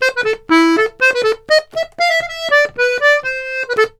S120POLKA3-R.wav